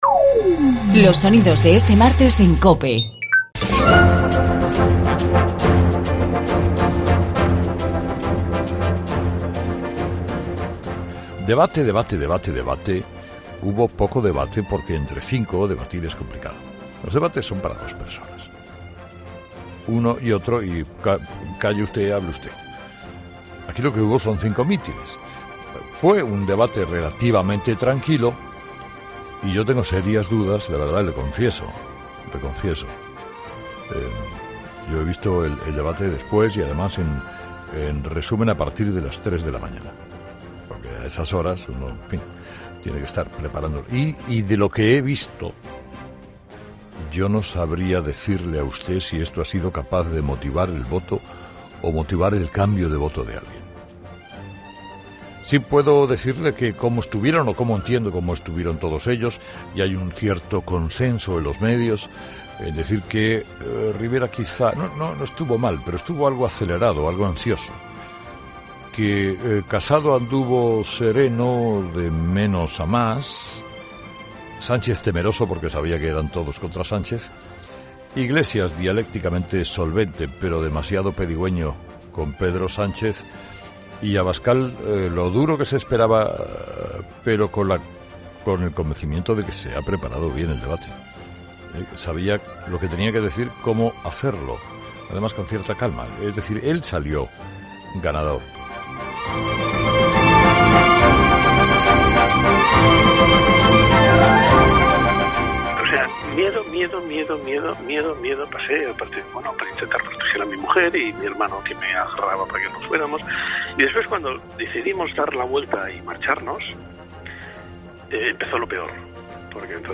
En su monólogo de este martes, Carlos Herrrera ha analizado el debate electoral entre los principales aspirantes a la Presidencia del Gobierno.
El toque de humor lo ha puesto un oyente, que corre "como un galgo", pero que para a los 50 metros a beber cerveza.